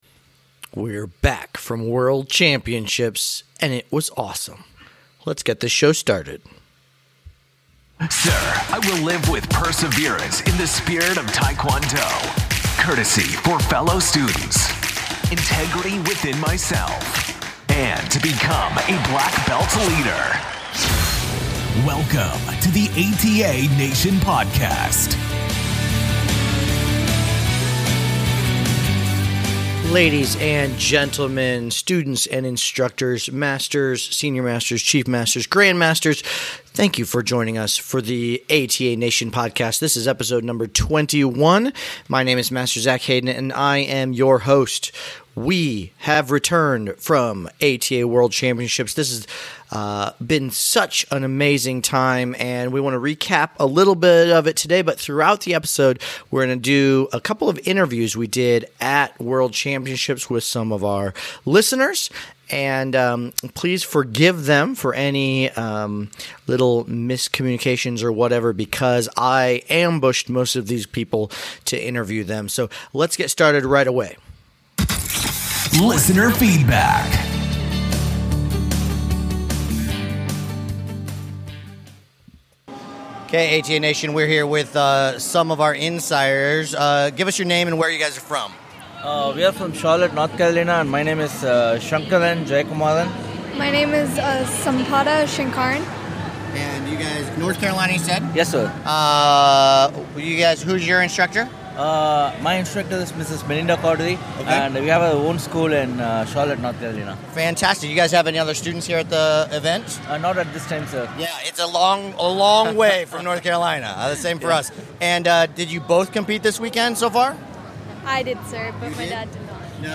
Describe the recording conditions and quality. In today's episode we feature some members of ATA Nation that we were able to talk to at Worlds this year.